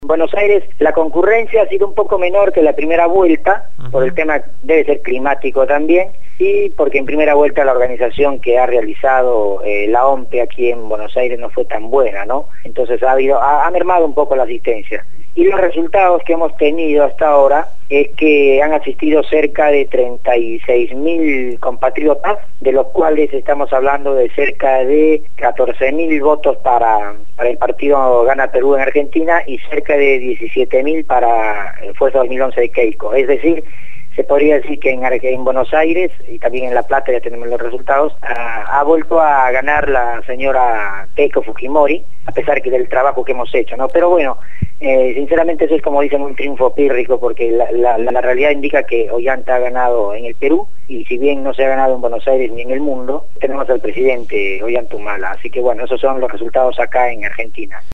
habló sobre la victoria de Ollanta Humala en el programa «Desde el Barrio» (lunes a viernes de 9 a 12 horas) por Radio Gráfica FM 89.3